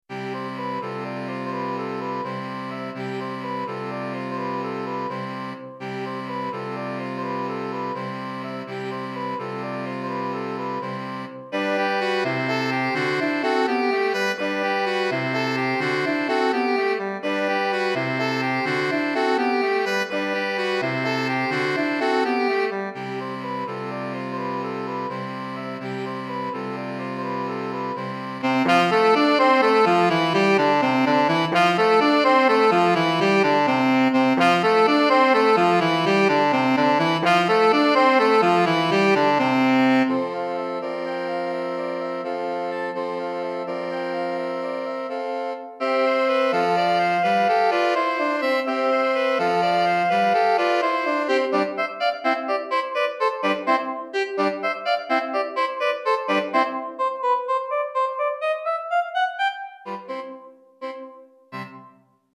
4 Saxophones